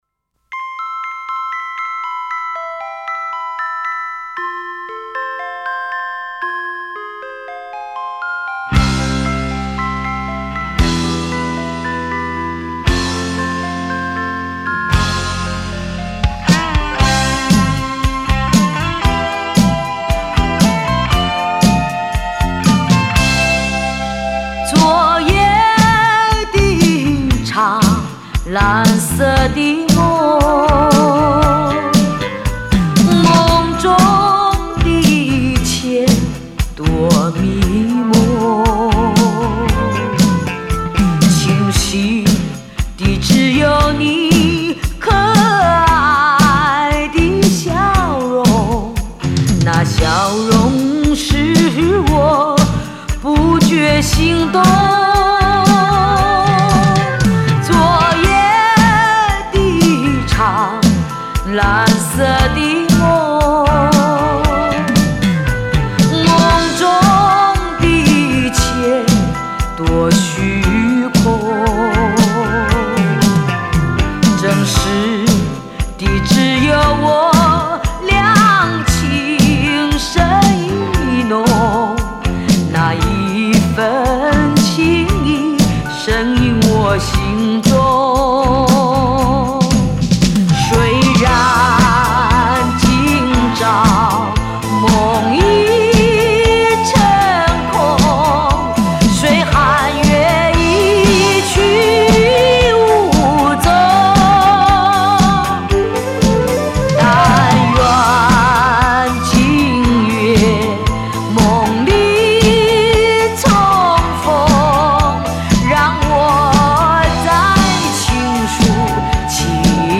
善变的演奏 瑰丽的美感 在梦中轻泻低沉的呼唤
里面大部分的老歌都谱上Disco节奏哈